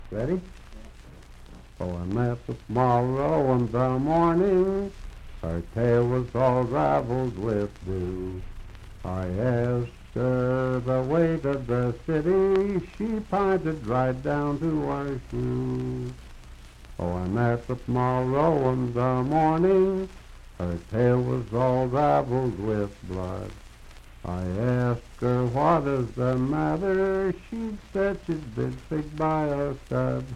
Unaccompanied vocal music
Bawdy Songs
Voice (sung)
Fairview (Marion County, W. Va.), Marion County (W. Va.)